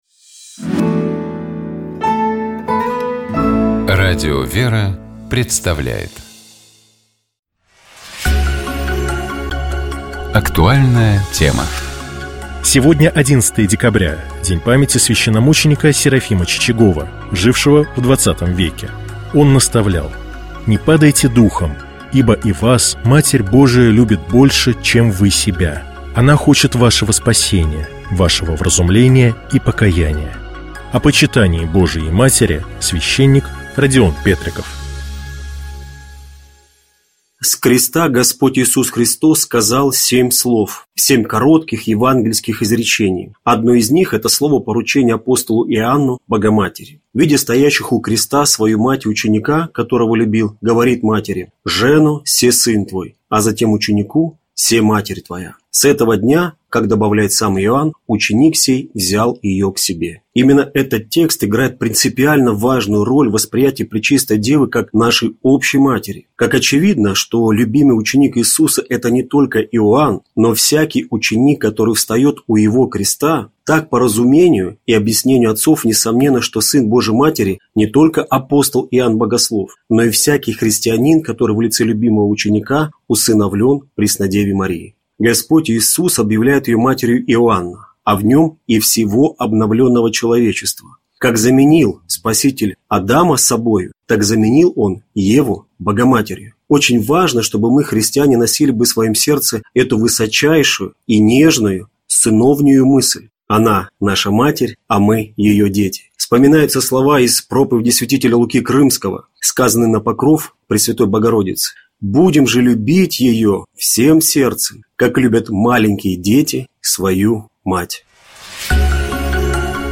О почитании Божьей Матери — священник